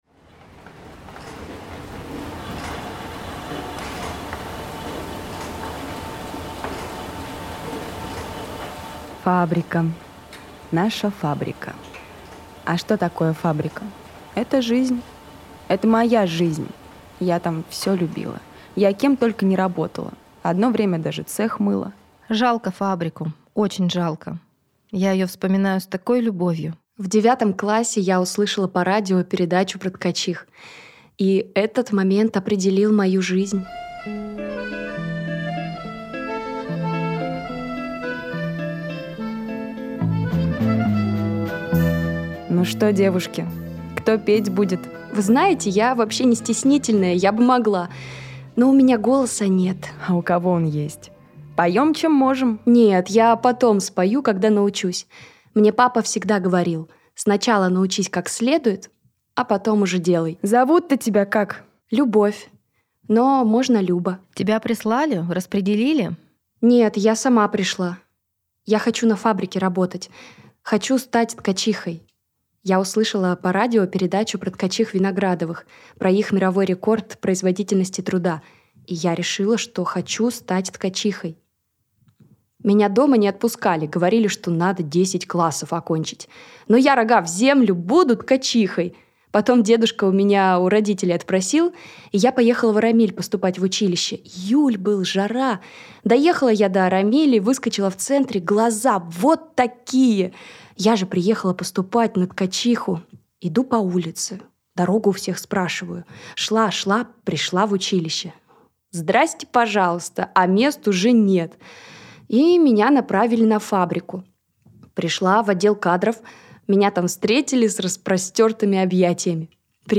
Все было как в настоящем театре.